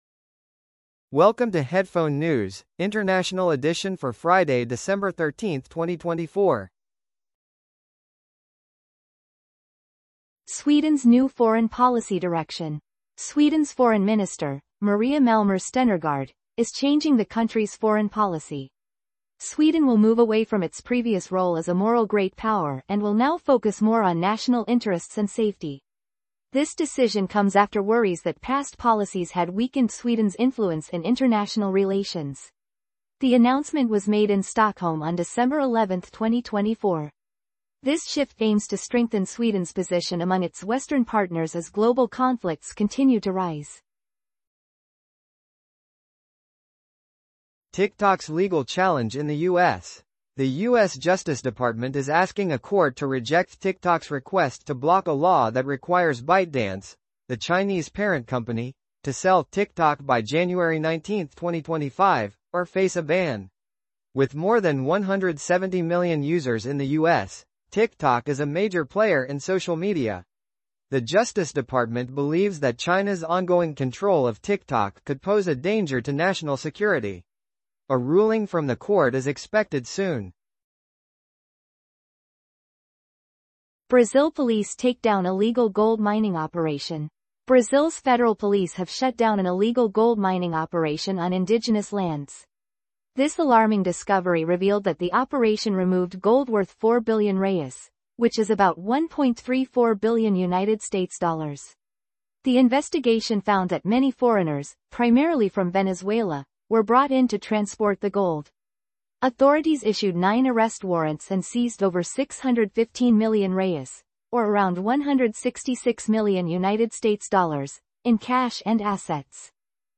The news are brought to you by A.I. 00:00:00 Intro 00:00:09 T